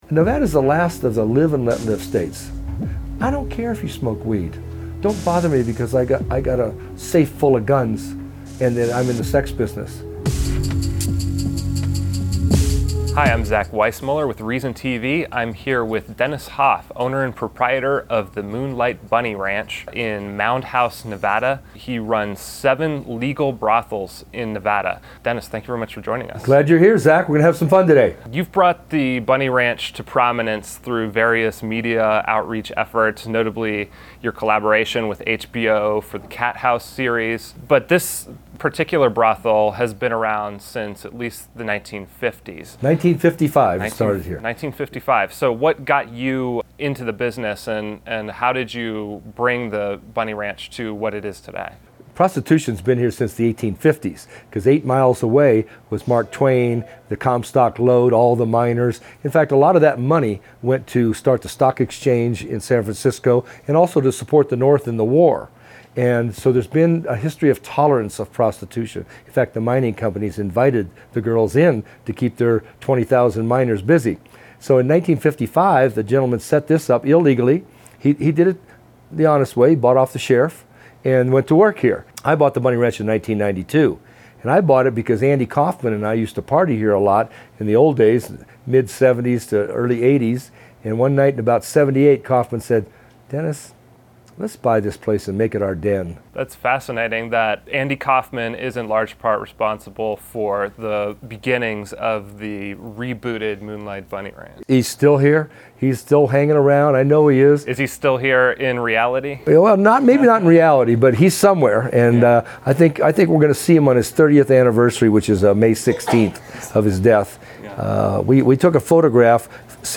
a wide-ranging interview about sex, prostitution, black markets, politics, and more.